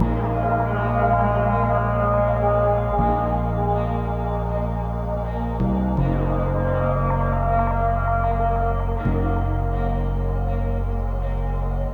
CANT LIE 161 BPM - FUSION.wav